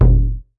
LOW DRUM.wav